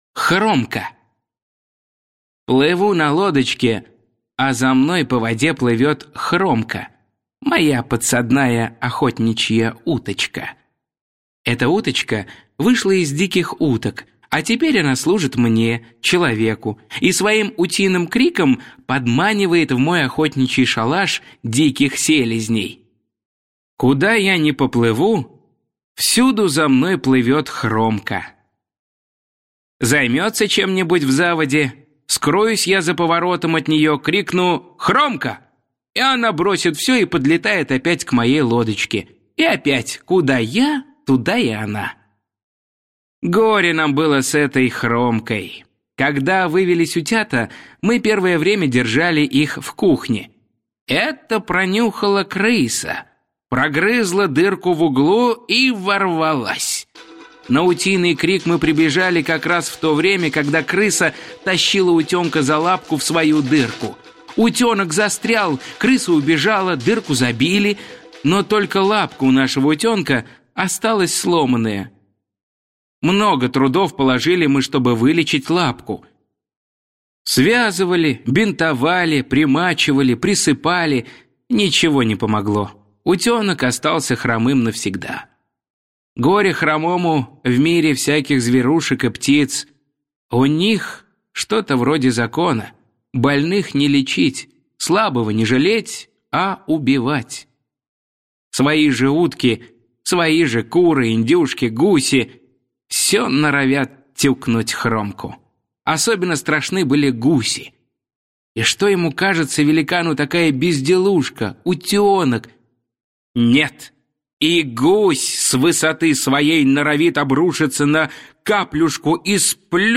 Хромка – Пришвин М.М. (аудиоверсия)